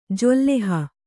♪ julāhi